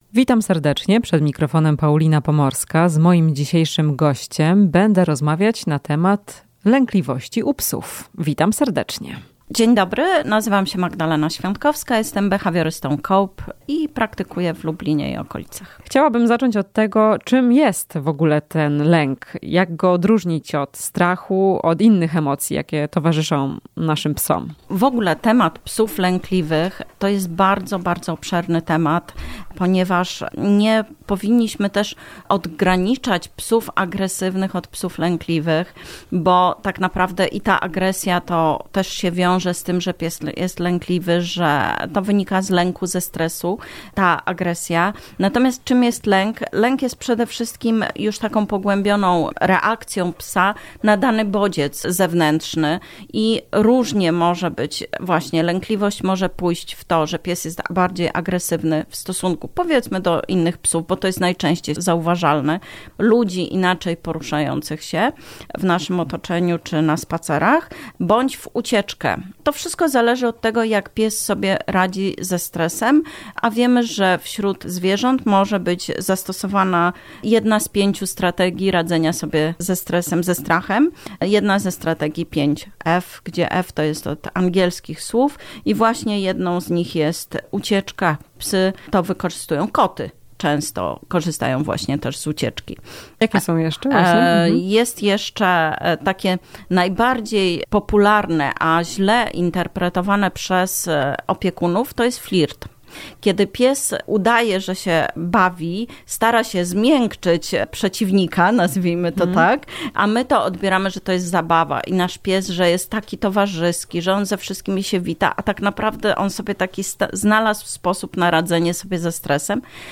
W „Chwili dla pupila” omawiamy czym jest lękliwość u psów i jak jest okazywana. Rozmowa